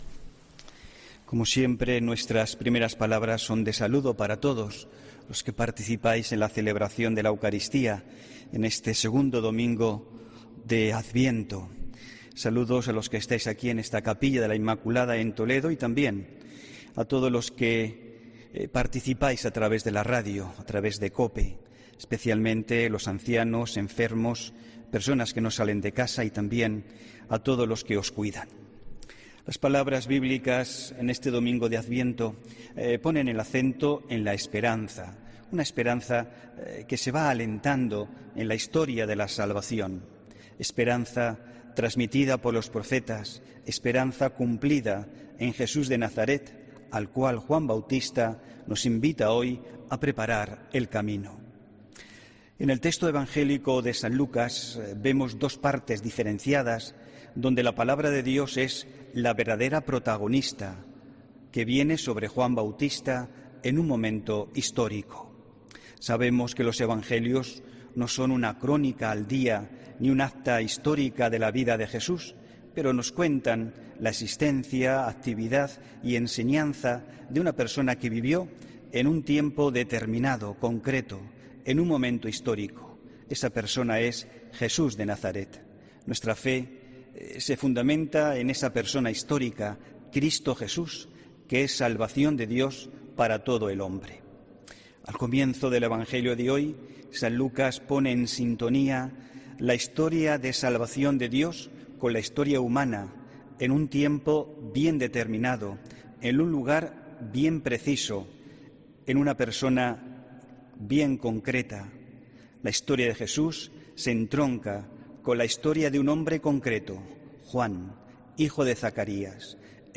HOMILÍA 5 DICIEMBRE 2021